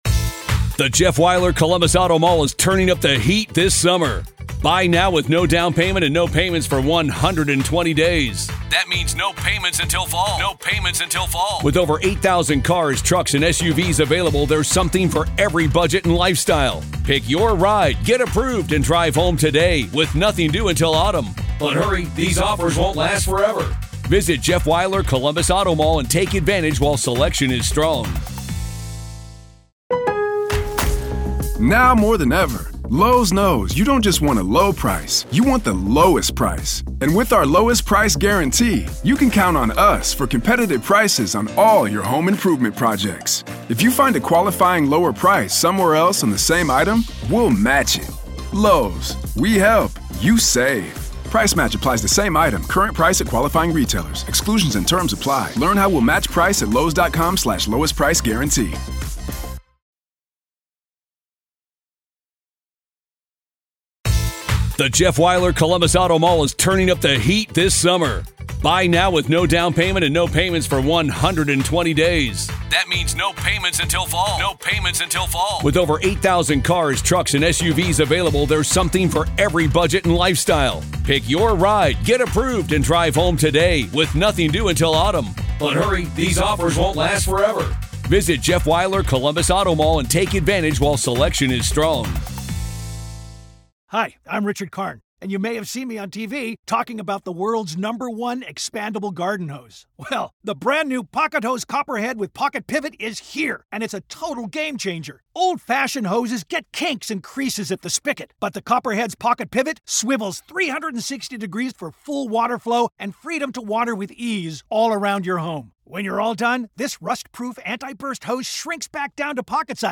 Kohberger Hearing-RAW AUDIO Part 1: Defense Makes Last Stand Before August Trial